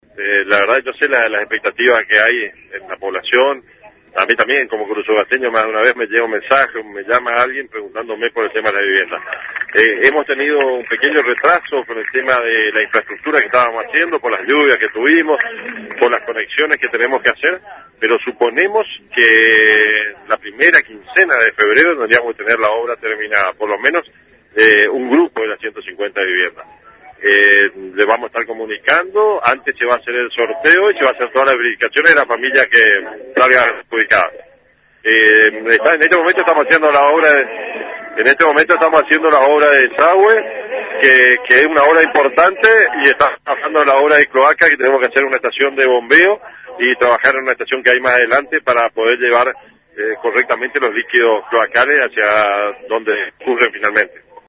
(Audio) El interventor del INVICO, contador Bernardo Rodríguez dialogó con IusNoticias y anticipó que el sorteo de las 150 viviendas que se encuentran detrás del cementerio municipal será en febrero.